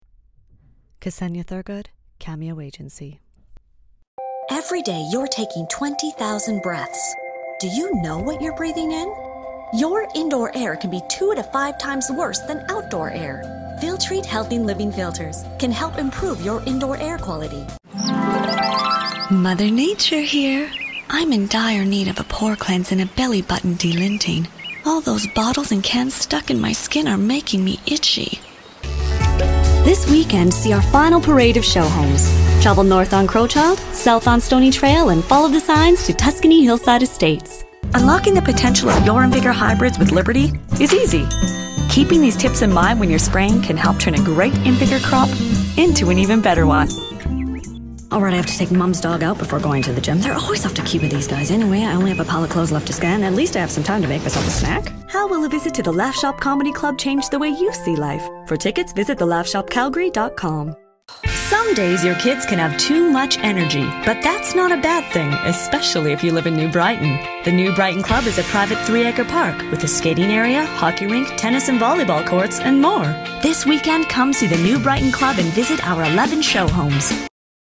Publicités - ANG